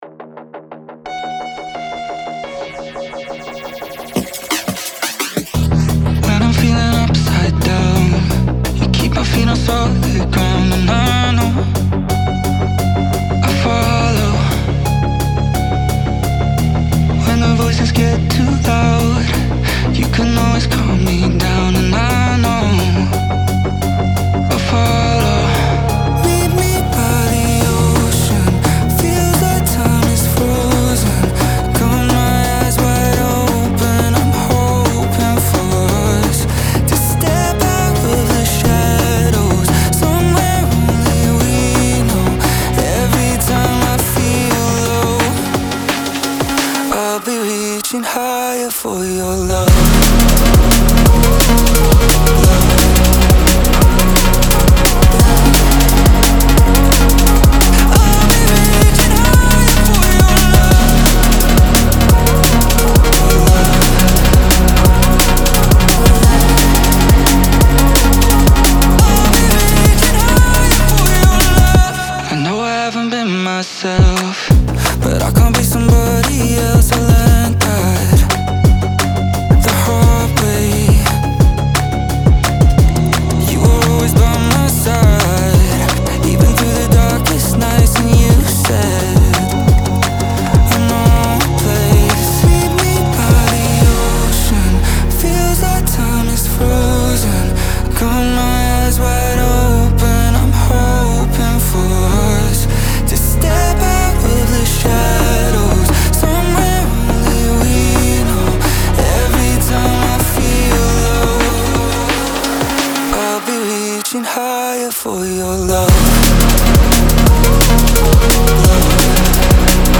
• Жанр: Dram&Bass